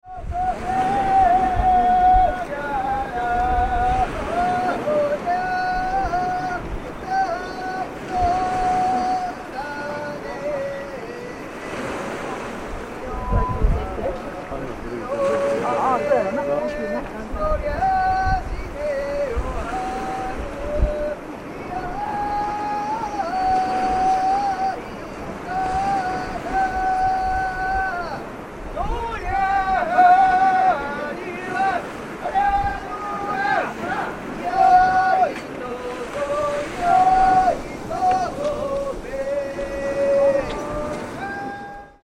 二見太鼓、木遣り唄とともに今年最後の張り替えが行われ、新しい年を迎えます。
木遣り(42秒・826KB)